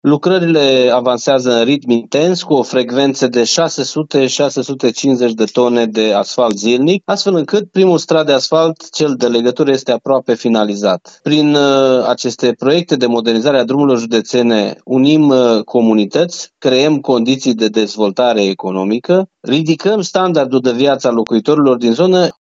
Modernizarea drumului județean Sânmartin-Socodor din județul Arad avansează conform graficului. În aceste zile este așternut și primul strat de asfalt, spune președintele Consiliului Județean Arad, Iustin Cionca.